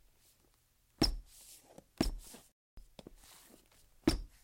鞋子吱吱响 202
描述：鞋在瓷砖地板上吱吱作响
Tag: 地板 尖叫声